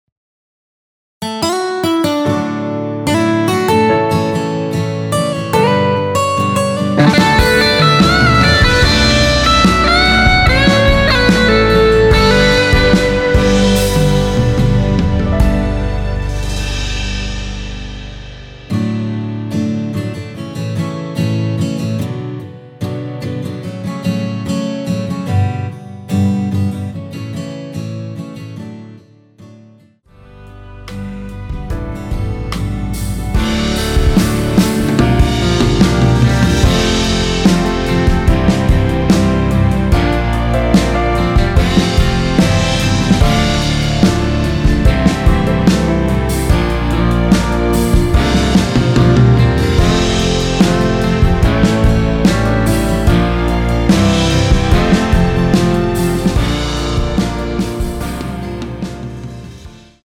남성분이 부르실수 있는키의 MR입니다.
원키에서(-5)내린 MR입니다.
D
앞부분30초, 뒷부분30초씩 편집해서 올려 드리고 있습니다.